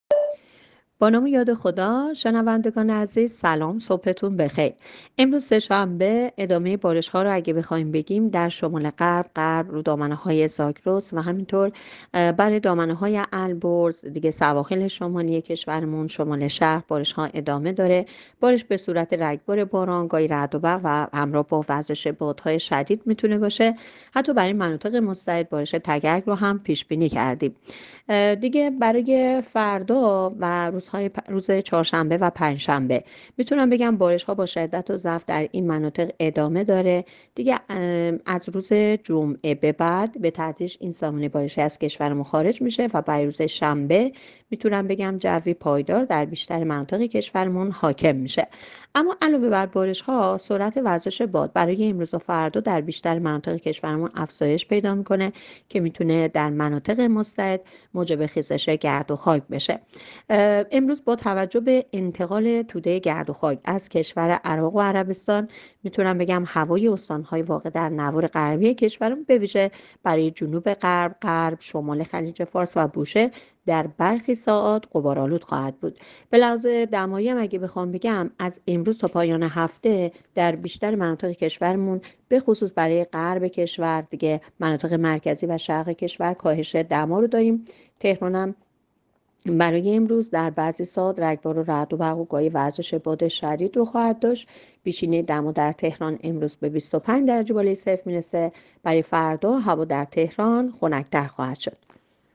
گزارش رادیو اینترنتی پایگاه‌ خبری از آخرین وضعیت آب‌وهوای ۲۶ فروردین؛